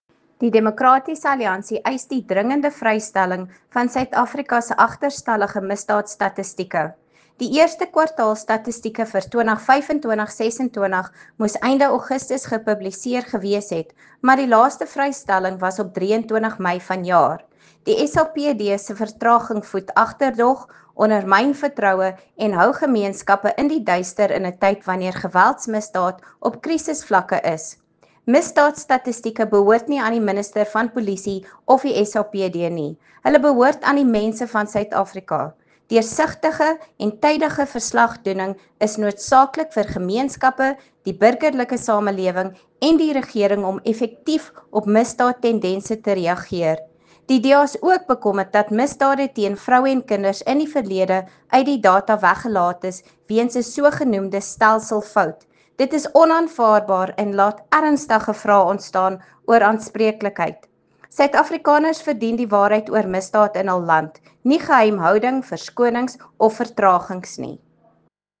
Afrikaans soundbite by Lisa Schickerling MP.